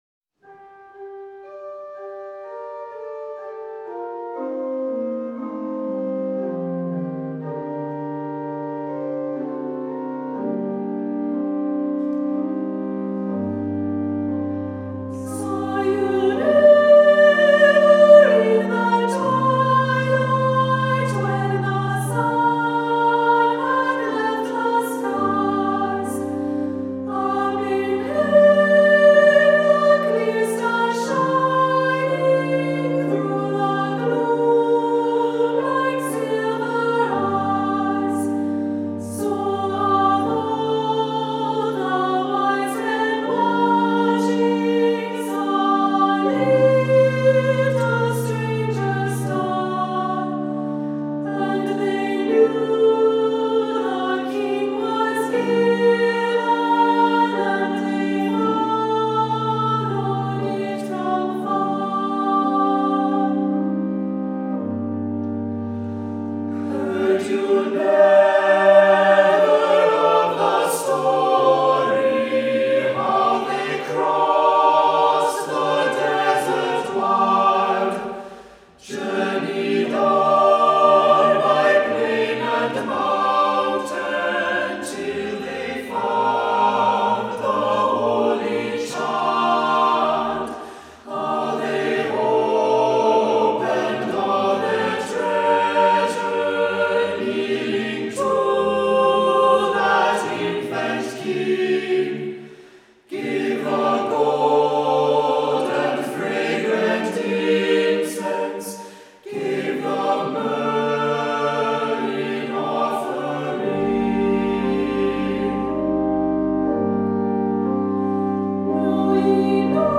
Voicing: Soloist or Soloists,SATB,Unison ChildrenÕs Choir